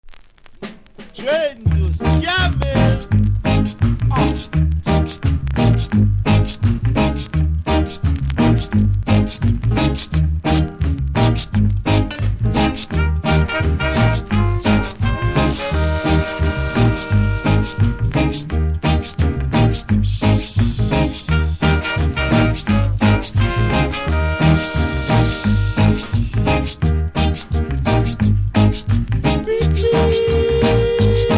フェードアウトにかかるぐらいなので問題はないでしょう。